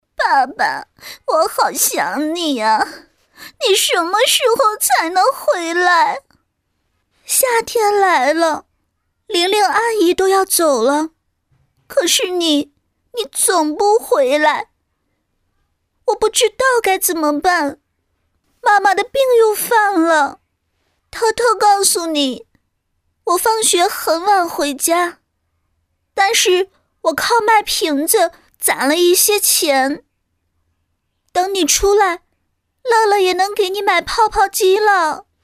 仿女童-女20-伪童小女孩8岁.mp3